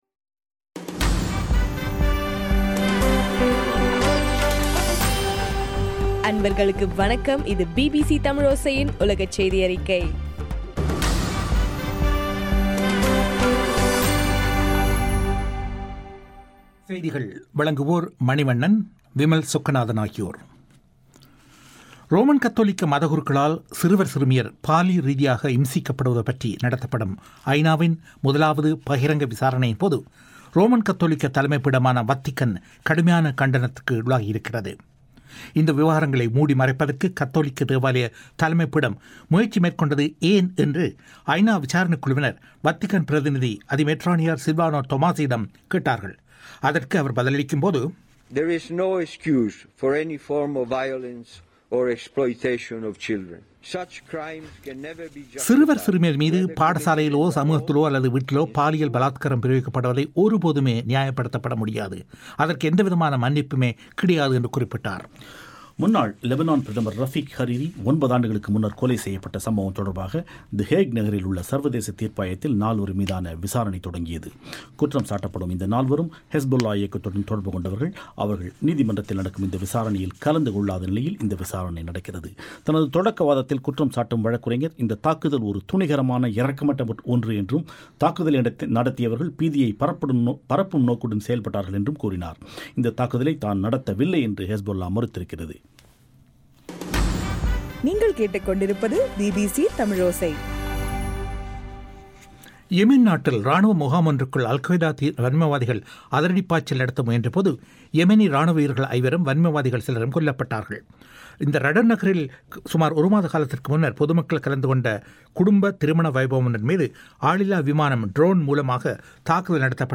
ஜனவரி 16 பிபிசியின் உலகச் செய்திகள்